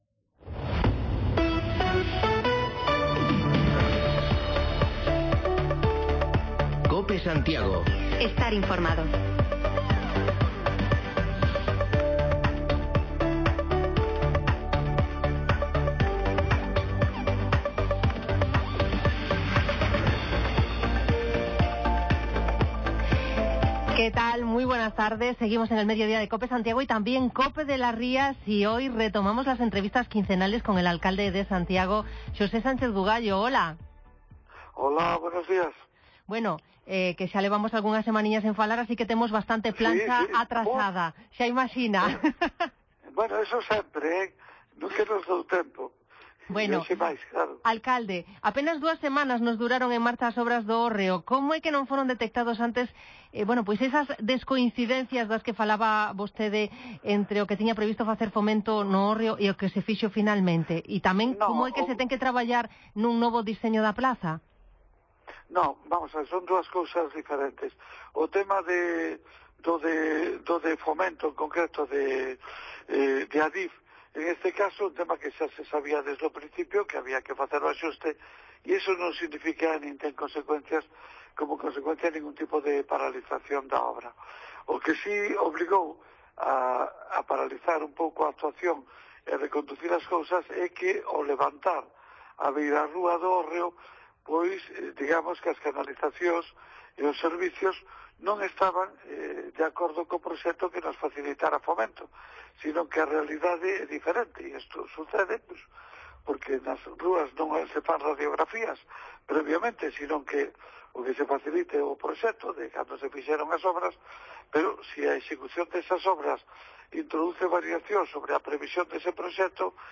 Retomamos las entrevistas quincenales con el alcalde de Santiago, Sánchez Bugallo: hoy tocaba hablar del parón en las obras del Hórreo apenas dos semanas después del inicio o los problemas con el botellón y las fiestas ilegales en la ciudad